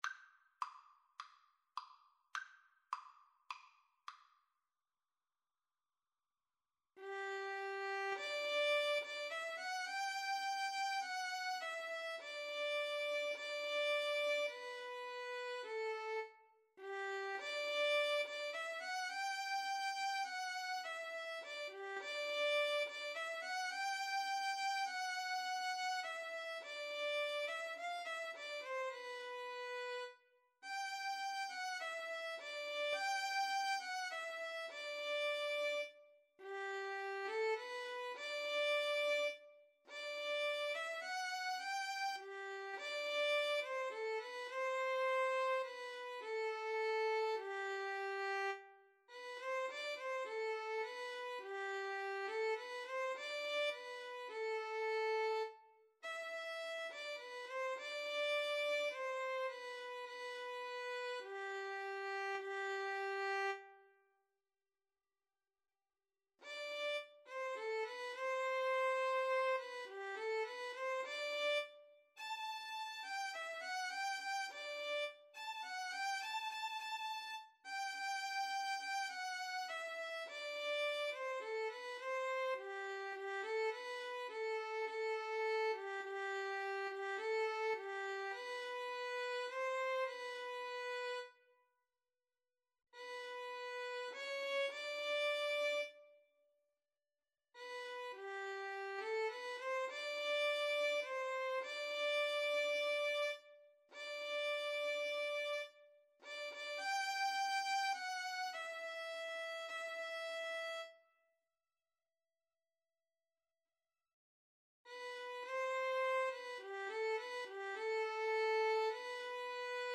Allegro ( = 104-120) (View more music marked Allegro)
Classical (View more Classical Violin-Viola Duet Music)